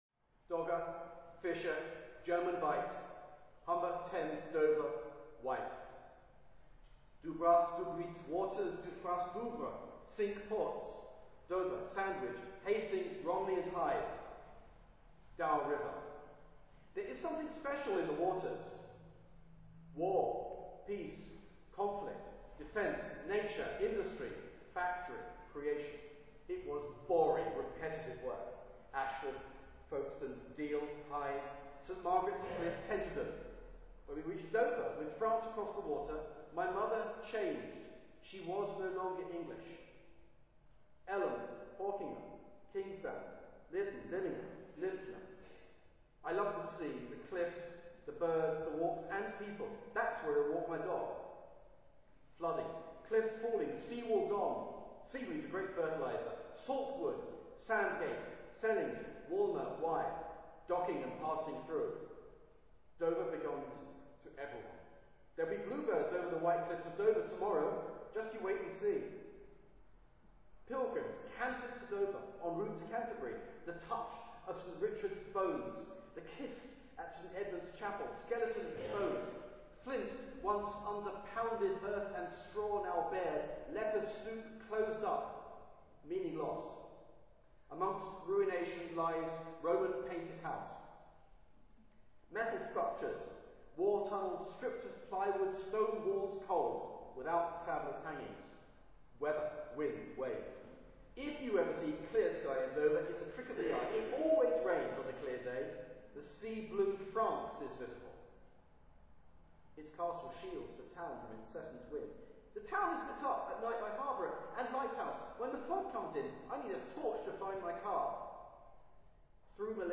11th October 2013. Dover Town Hall (Maison Dieu)